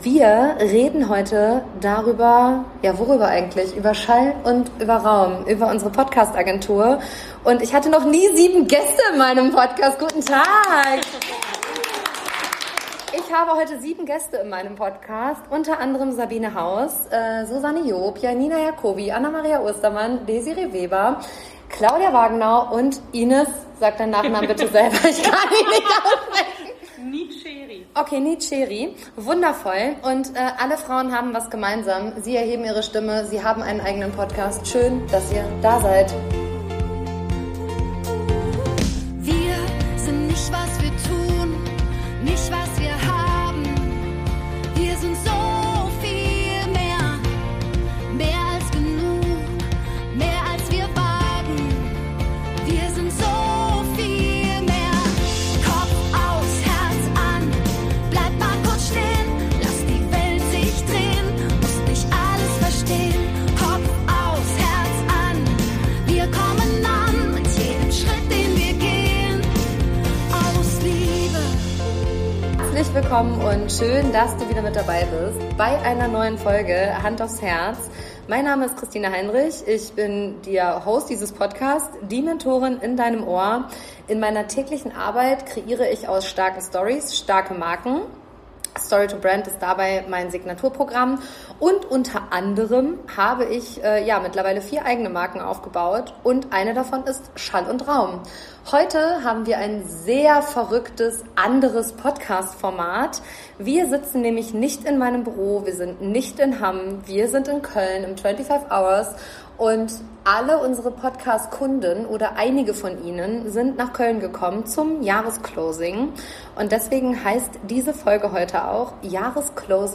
Sieben meiner wundervollen Podcastkundinnen sind dabei und teilen ihre Erfahrungen, Learnings und ihre ganz persönlichen Gründe, warum sie mit ihrem Podcast gestartet sind.